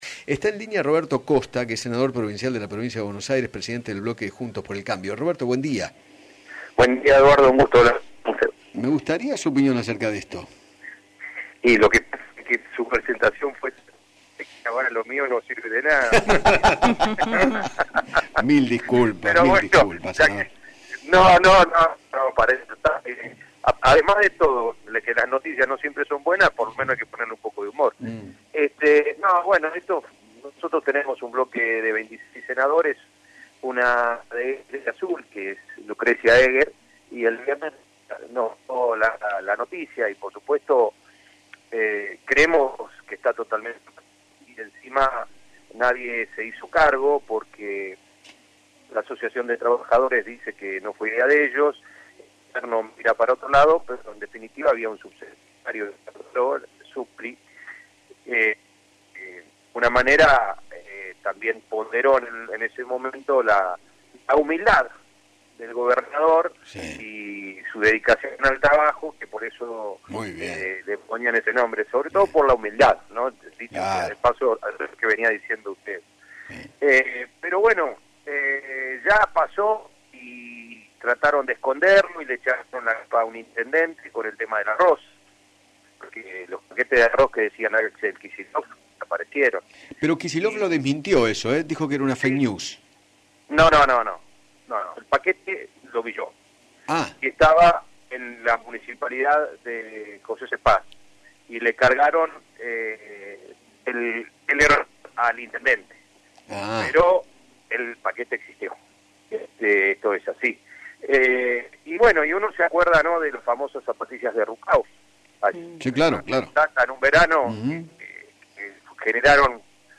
Roberto Costa, Presidente del bloque de Juntos por el Cambio en el Senado, dialogó con Eduardo Feinmann sobre la inauguración de un parador de aseo para camioneros con el nombre “Gobernador Axel Kicillof” , en la localidad de Azul, centro de la provincia de Buenos Aires. Además, se refirió a los contagios en Villa Azul.